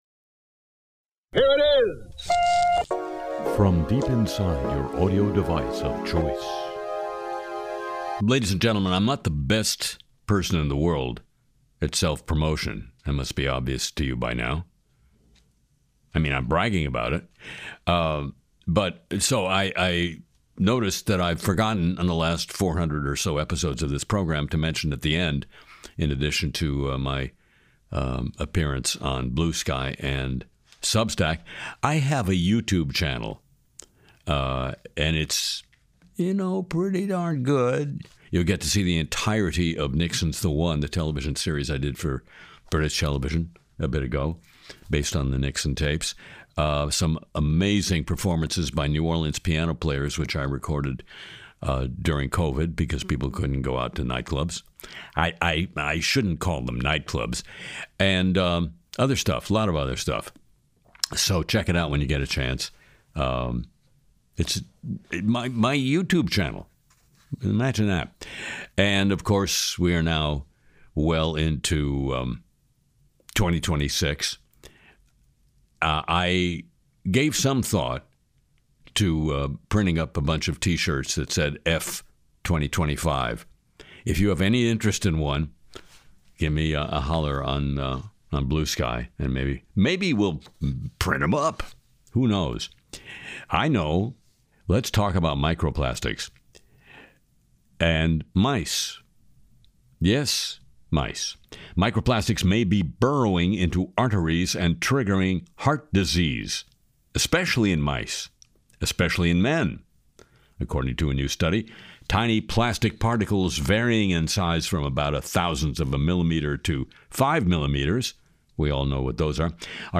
Harry sings “Boots on the Ground,” skewers Trump’s Venezuela claims on Truth Social, digs into AI chat abuse, meme coins, EV shakeups, Grok backlash, and Apologies of the Week.